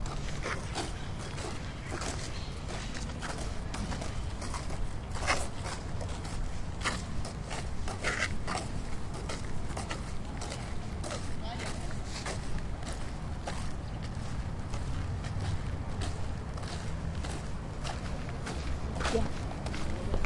孩子们在公园里散步。我们用便携式录音机录制，变焦H2。